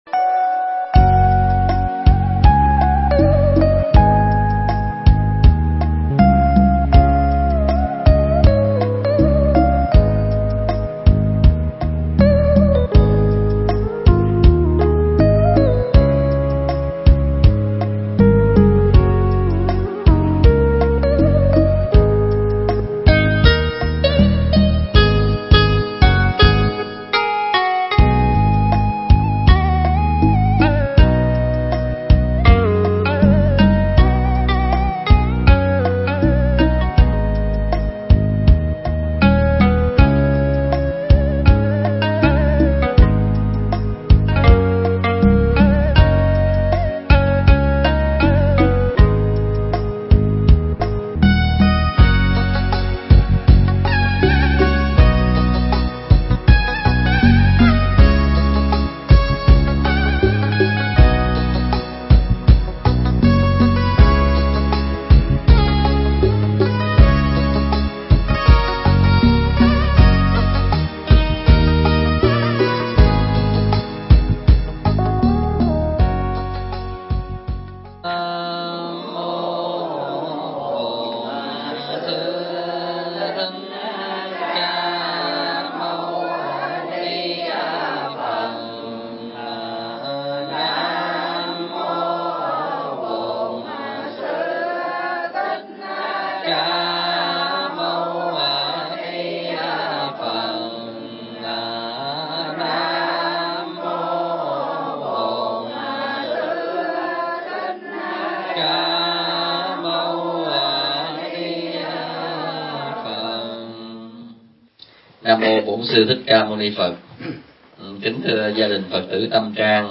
Mp3 Pháp Thoại
giảng tại Đạo Tràng Phổ Hiền (Bang Washington, Hoa Kỳ)